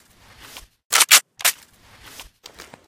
m4a1_misfire.ogg